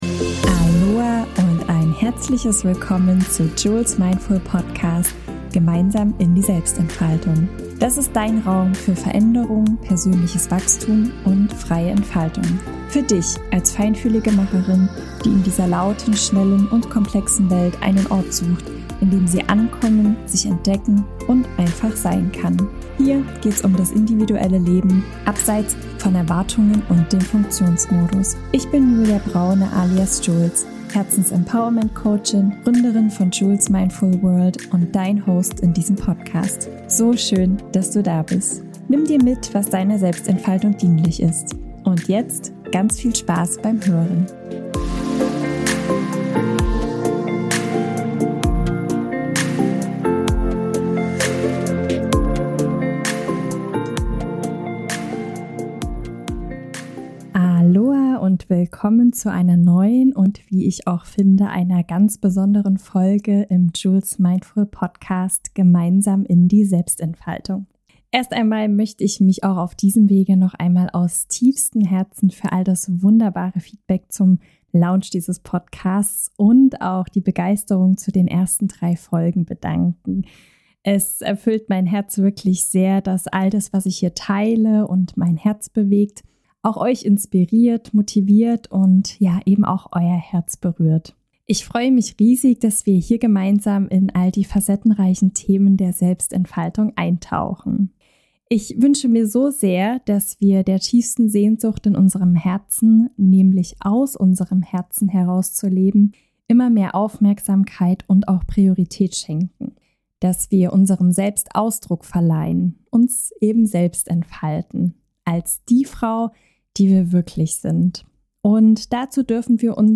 Mit geführten Achtsamkeitsübungen und inspirierenden Visualisierungen kannst du lernen, dich aus deinem Herzen heraus mit deinen Träume zu verbinden, um schließlich dein selbstbestimmtes Leben zu gestalten.